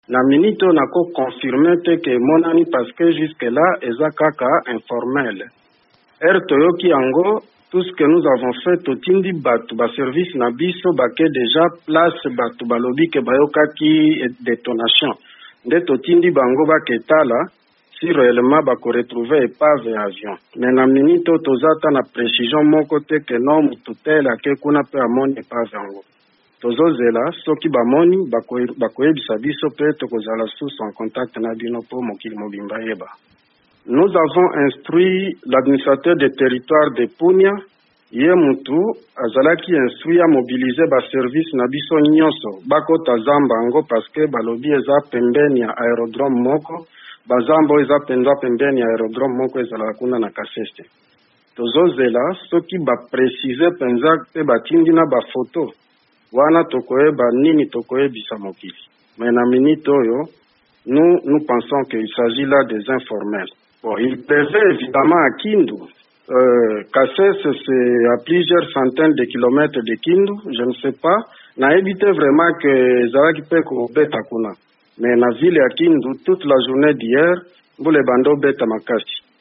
Jean-Pierre Amadi, vice-gouverneur ya Maniema ayanoli na mituna ya VOA Lingala mpo etali Antonov 72 eye ebungi uto jeudi na etuka na ye. Alobi batindi mampinga ya bolukiluki na Punia, na zamba, pene na aérodrome moko na Kasese epayi bato balobi bayokaki lokito, Toyoka ye.